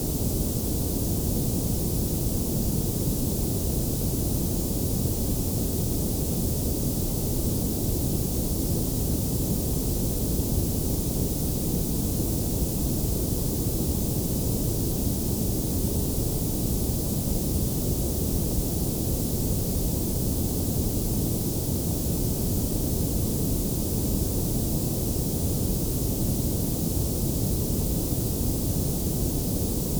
🎧 Test an Engine-Ready Ambience Loop
Download a seamless loop sample and test it directly in your engine:
➡ Direct WAV download (Deep Tension 30s)
deep_tension_normal_engine_ready_30s.wav